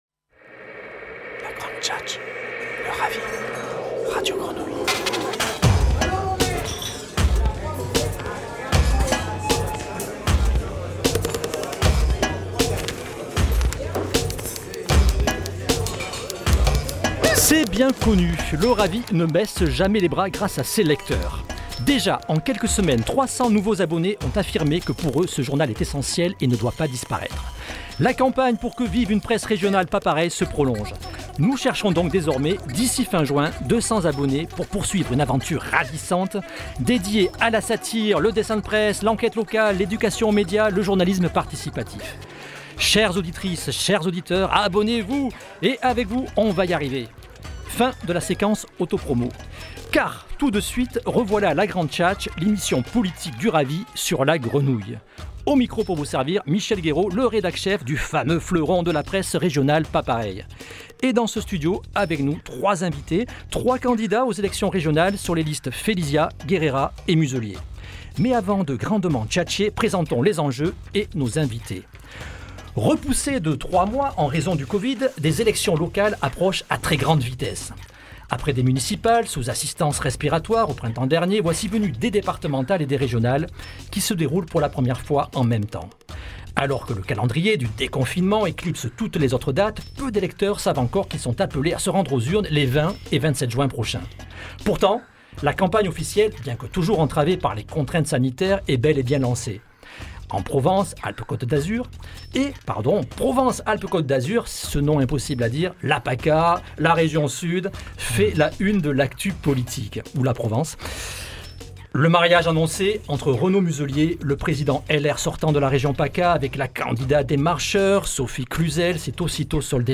Entretien en partenariat avec Radio Grenouille